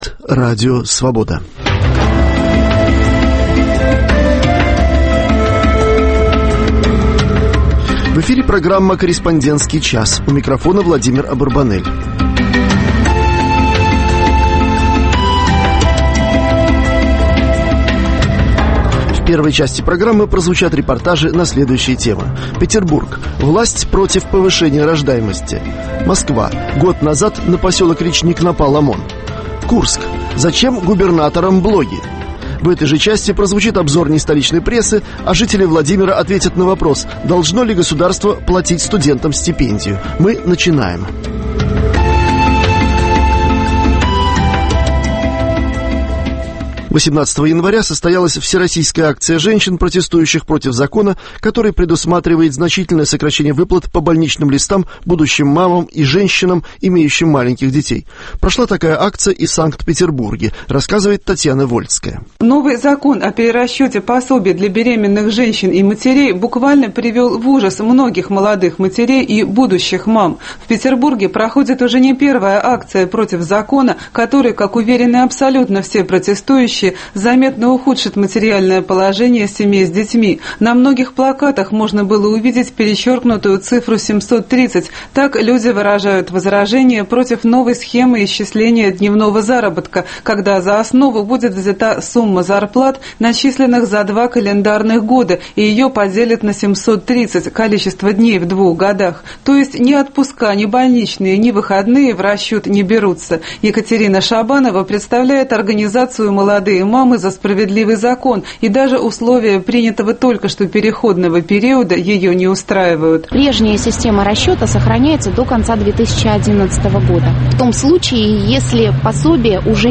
Еженедельная серия радиоочерков о жизни российской провинции. Авторы из всех областей России рассказывают о проблемах повседневной жизни обычных людей.